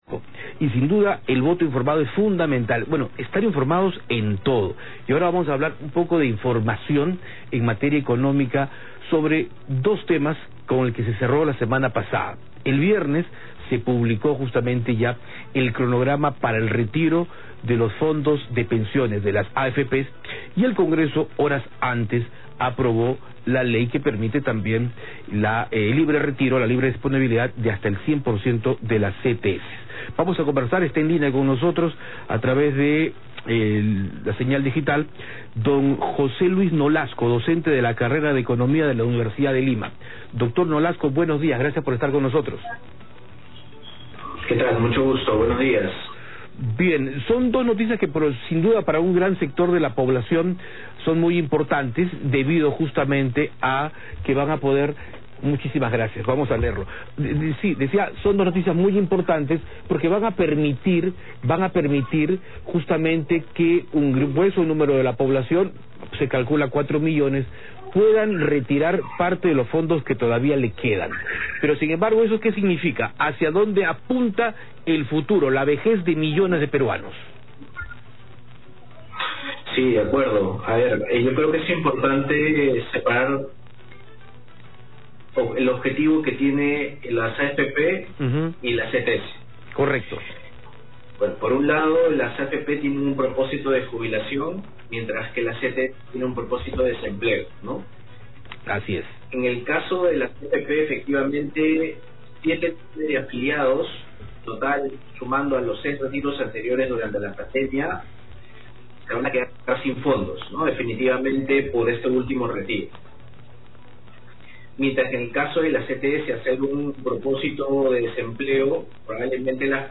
Economista Ulima advierte sobre los riesgos del retiro de AFP y CTS